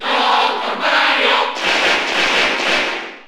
Category: Crowd cheers (SSBU) You cannot overwrite this file.
Dr._Mario_Cheer_Spanish_NTSC_SSB4_SSBU.ogg